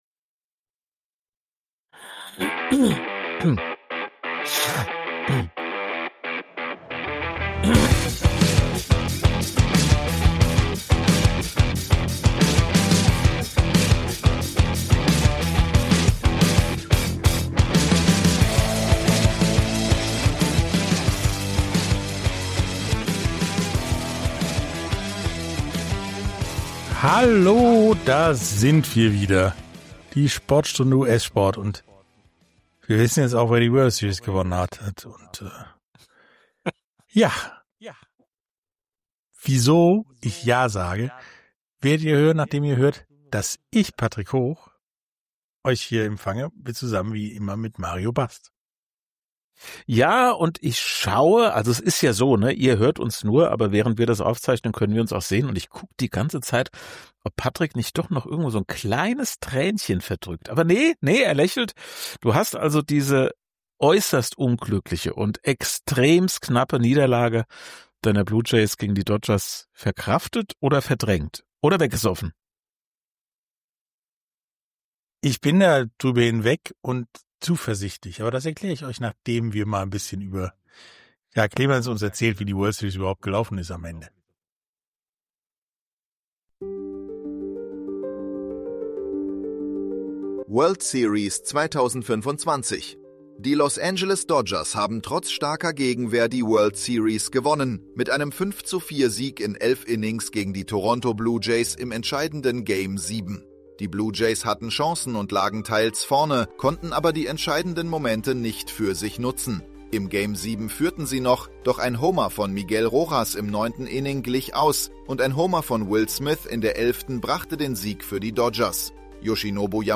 Dazu gibt’s ein spannendes Interview